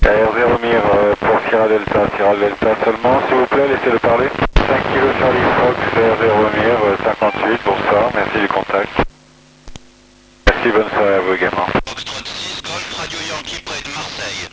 QSO PHONIE avec MIR